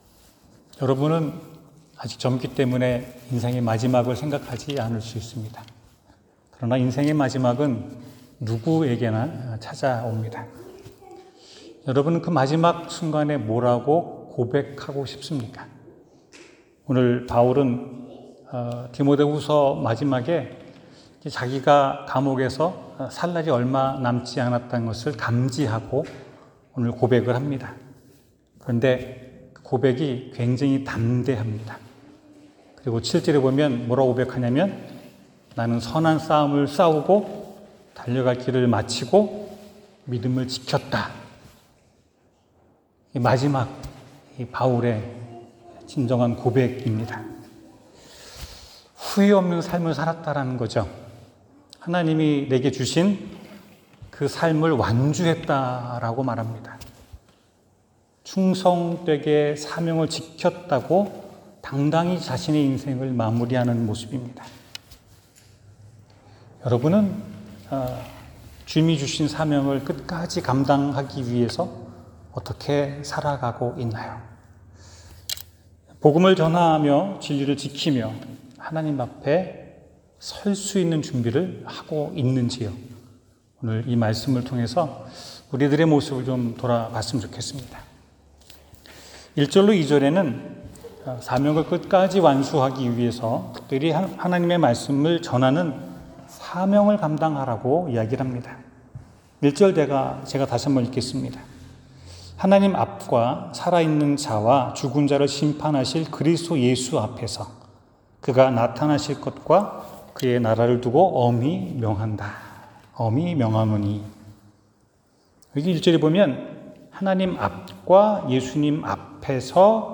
사명을 끝까지 완수하라 성경: 디모데수허 4:1-8 설교